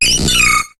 Cri de Marill dans Pokémon HOME.